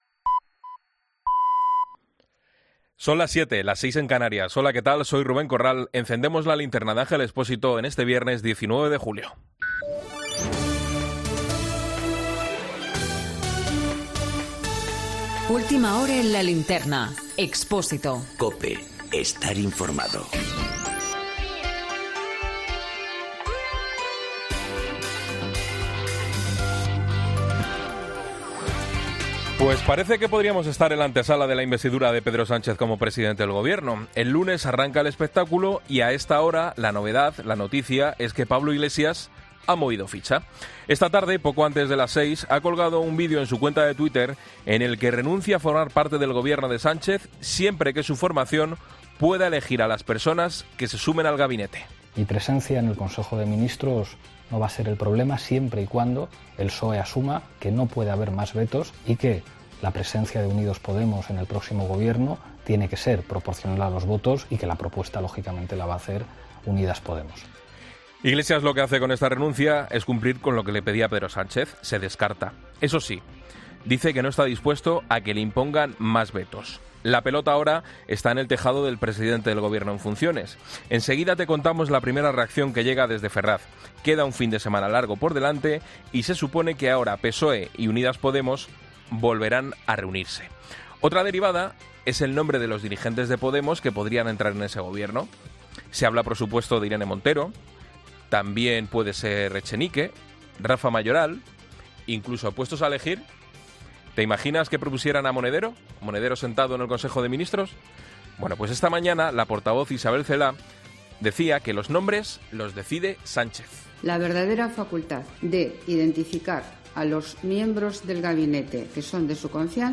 Boletín de noticias de COPE del 19 de julio de 2019 a las 19.00 horas